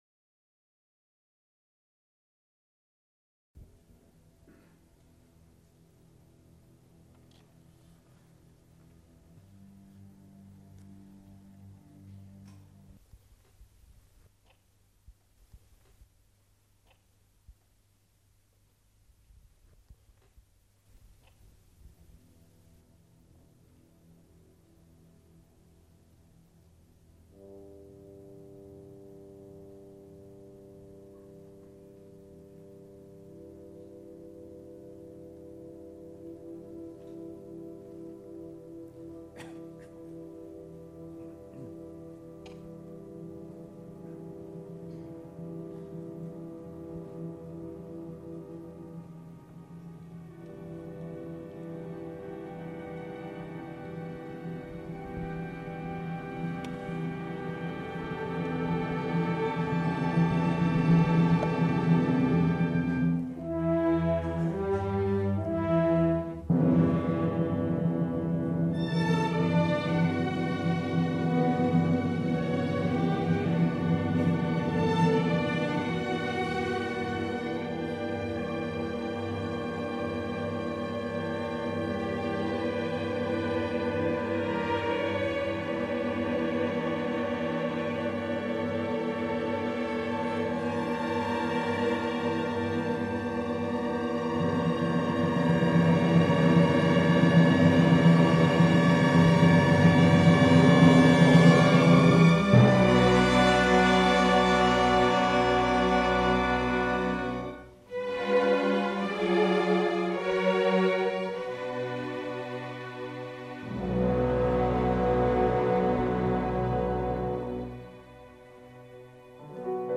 Φανταστική Συνομιλία με τον Ed. Grieg για σόλο πιάνο και ορχήστρα (live από πρόβα)